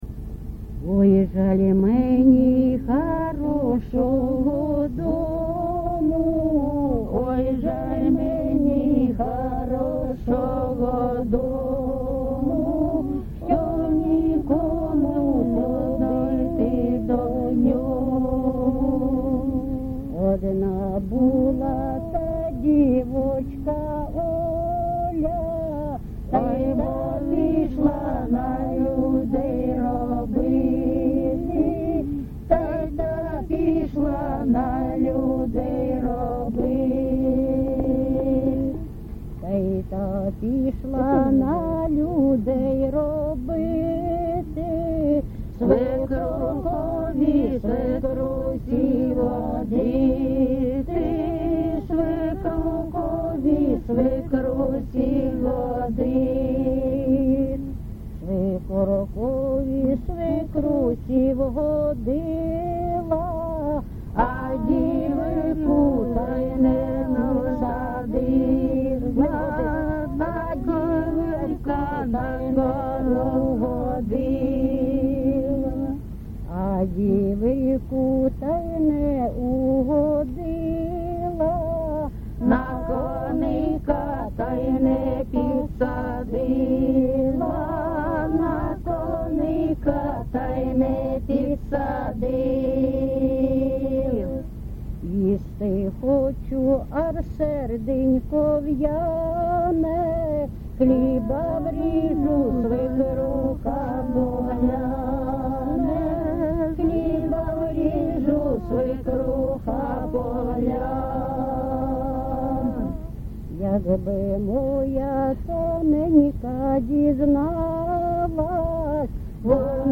ЖанрВесільні
Місце записус. Богородичне, Словʼянський район, Донецька обл., Україна, Слобожанщина